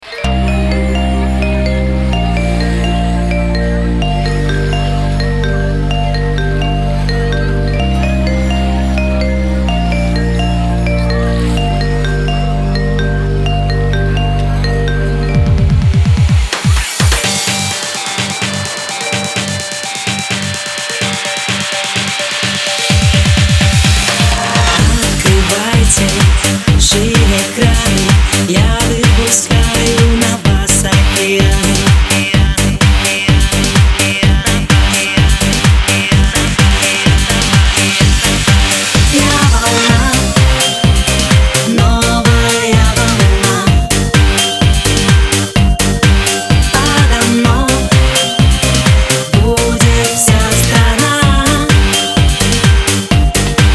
• Качество: 320, Stereo
громкие
remix
веселые
EDM
electro house
Популярная в 2010-м году песня в заводном ремиксе